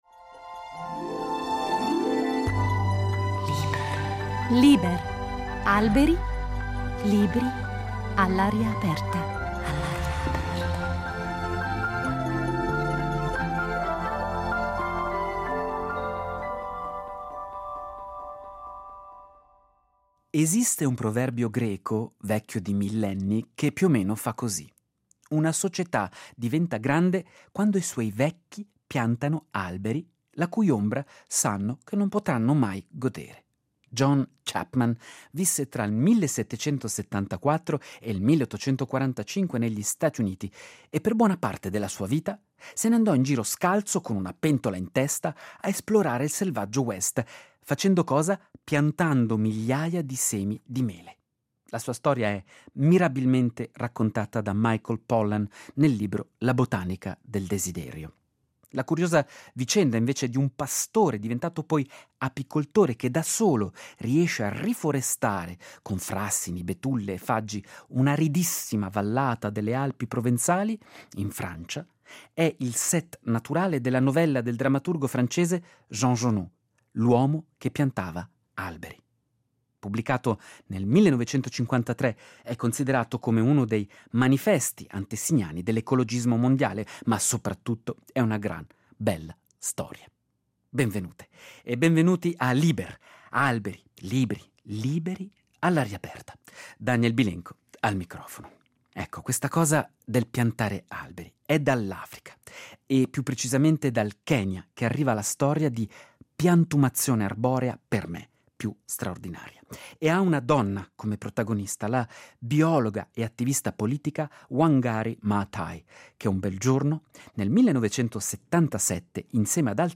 Con gli innesti botanici all’aria aperta dell’esperta di piante tossiche e curative